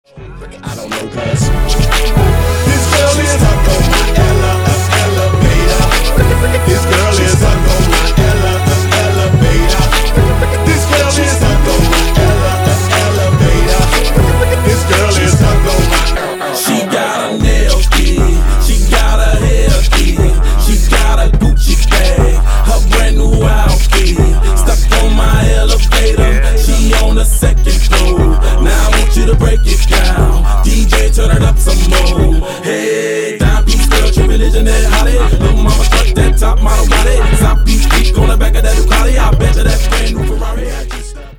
• Качество: 192, Stereo
мужской голос
Хип-хоп
речитатив